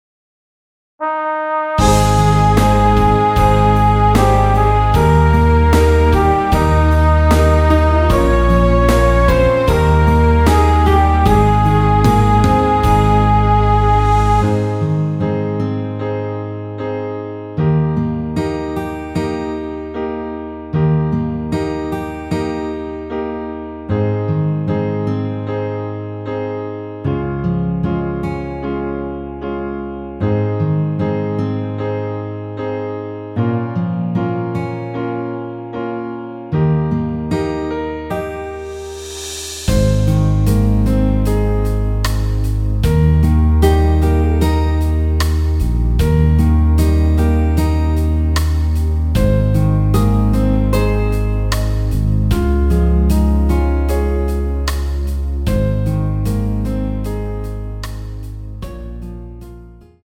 올린 MR 입니다.
원곡의 보컬 목소리를 MR에 약하게 넣어서 제작한 MR이며